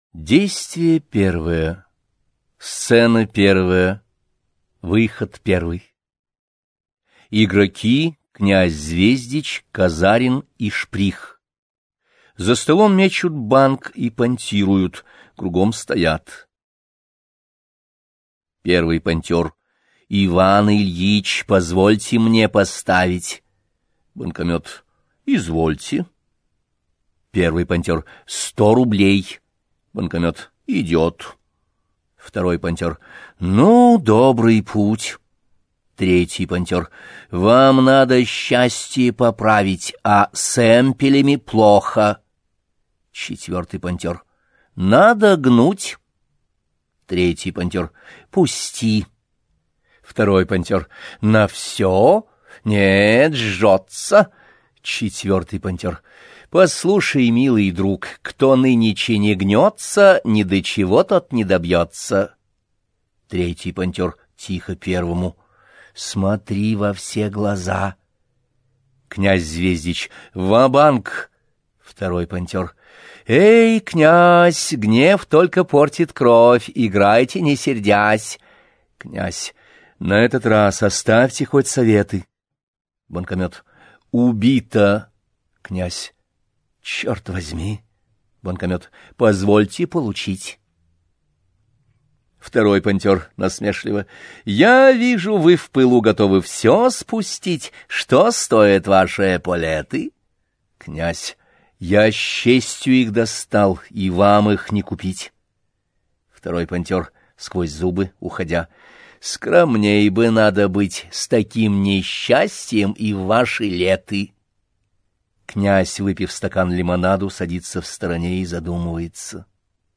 Аудиокнига Маскарад: Драма в 4-х действиях, в стихах | Библиотека аудиокниг